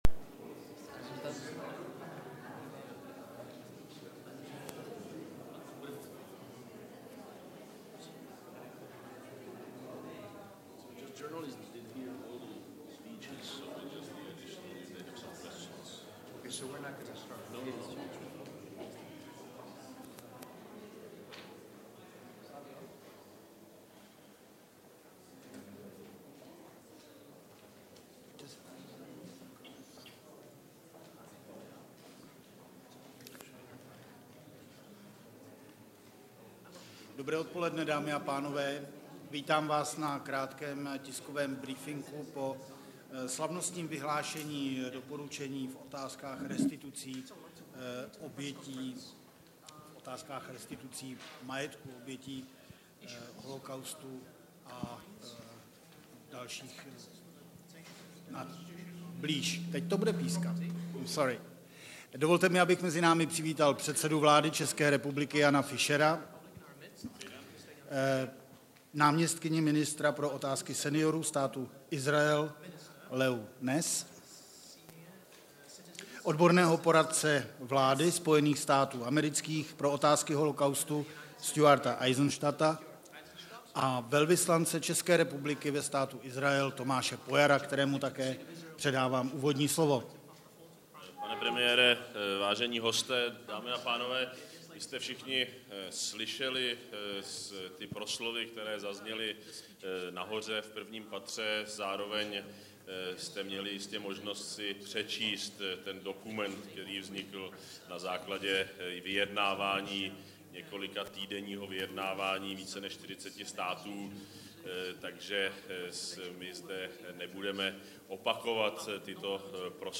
Tiskový brífink po slavnostním vyhlášení doporučení v otázkách restitucí majetku obětí holocaustu, 9.6. 2010